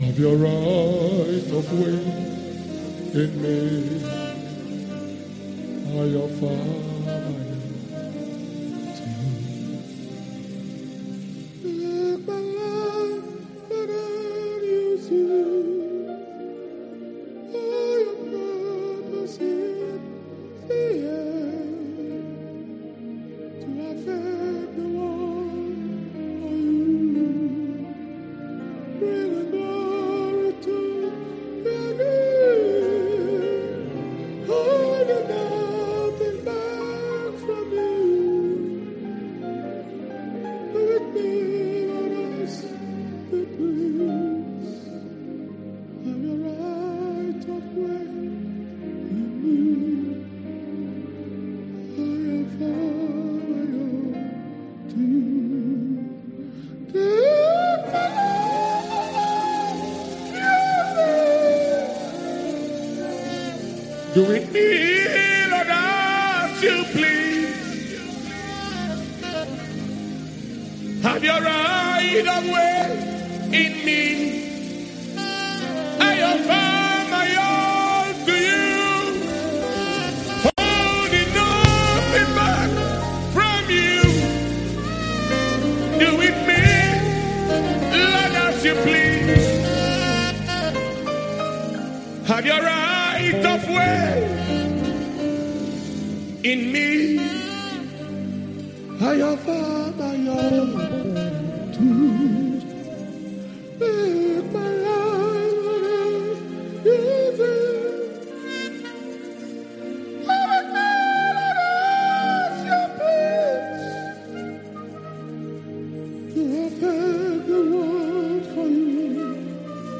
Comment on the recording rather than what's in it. A powerful message at the 2020 International Ministers Fire Conference Download MP3 Play X YouTube Channel Here INTERNATIONAL MINISTERS’ FLAMING FIRE CONFERENCE/POWER COMMUNION SERVICE – WEDNESDAY, 26TH AUGUST 2020 ANCHOR SCRIPTURE(S